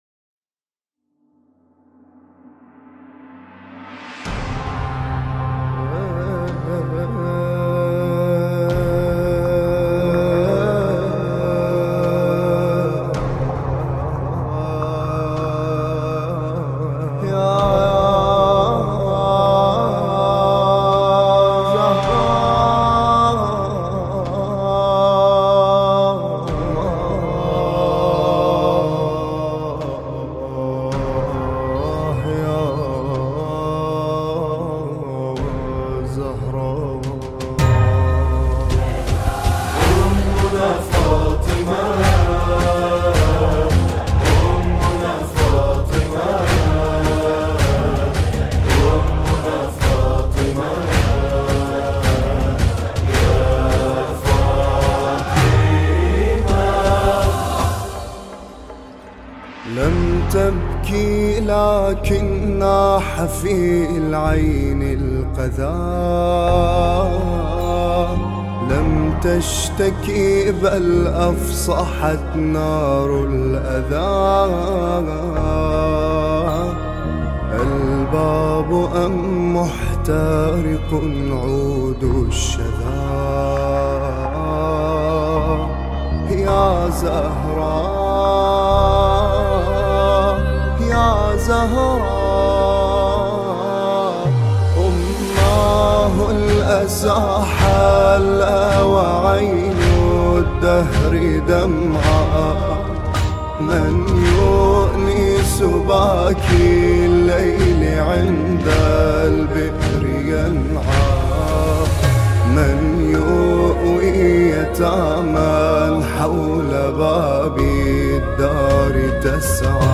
مداحی عربی